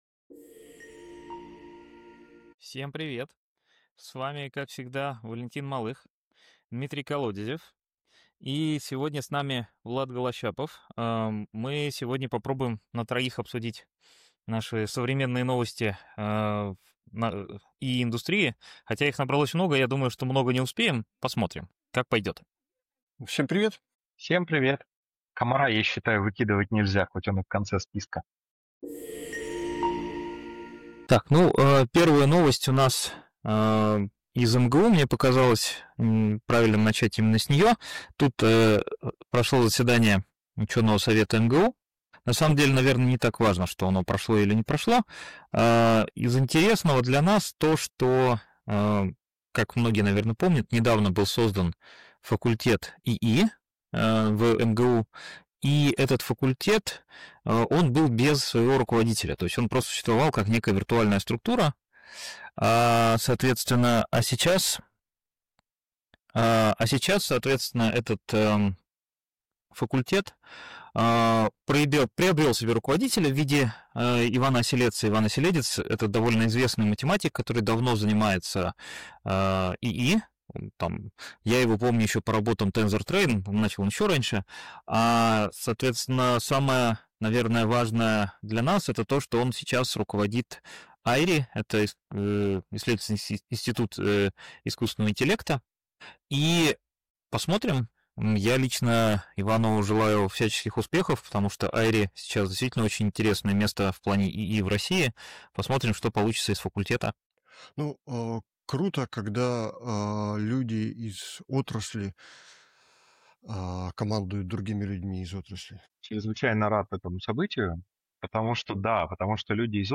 Двадцатый выпуск подкаста Капитанский мостик, обсуждение новостей из мира ИИ за прошедшую неделю и не только.
В этом разговоре участники обсуждают актуальные события в области искусственного интеллекта и микроэлектроники в России, включая создание факультета ИИ в МГУ, развитие отечественного железа и проблемы, с которыми сталкивается микроэлектроника.